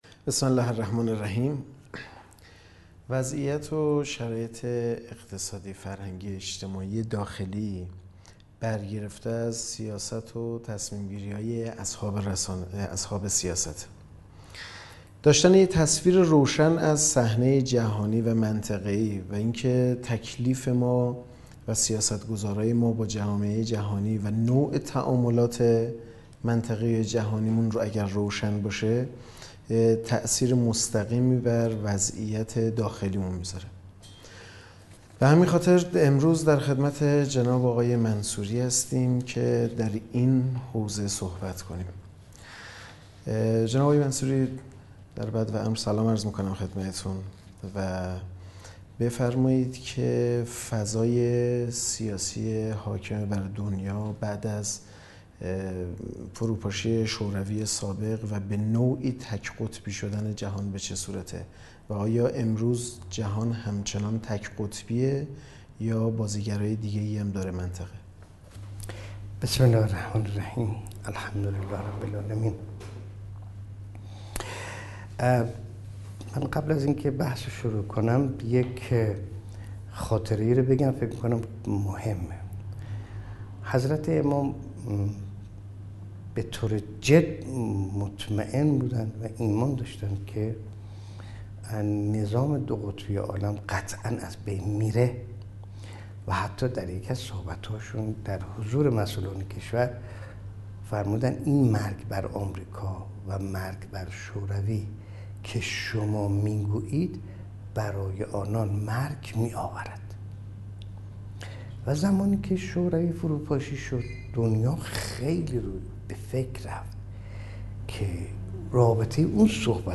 گفت‌وگوی دیدار در برنامه «ایرانشهر» با یک حقوقدان و وکیل دادگستری